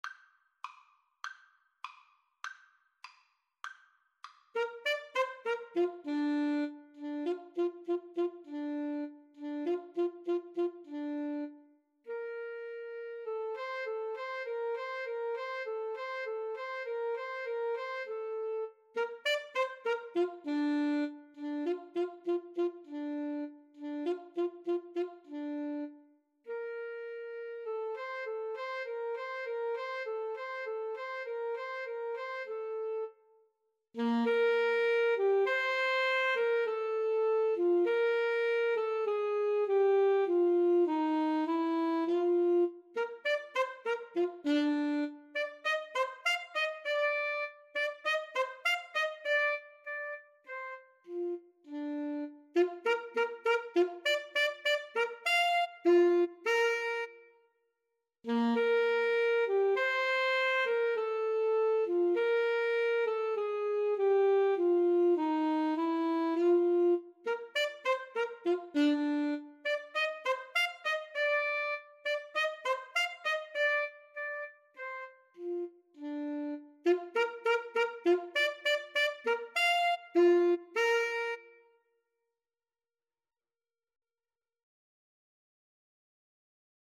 Free Sheet music for Flute-Saxophone Duet
Bb major (Sounding Pitch) (View more Bb major Music for Flute-Saxophone Duet )
2/4 (View more 2/4 Music)
Classical (View more Classical Flute-Saxophone Duet Music)